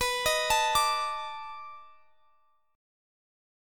Listen to B7#9 strummed